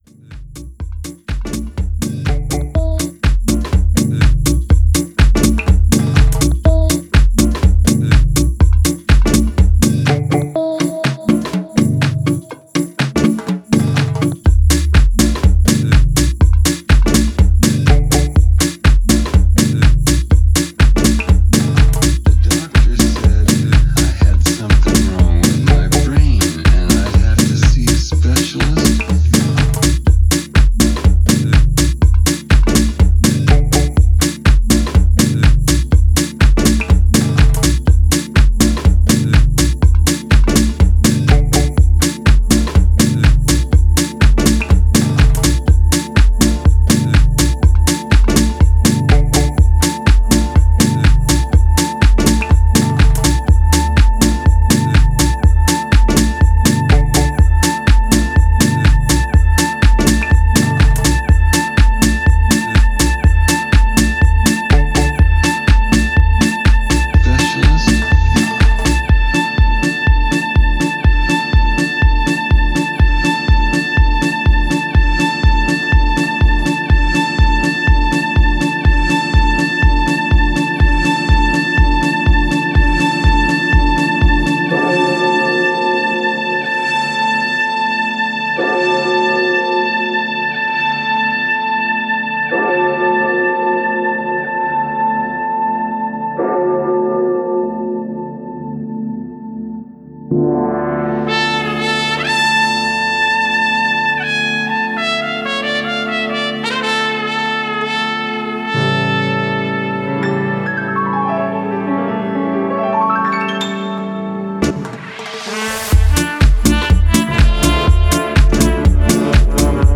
Style: Tech House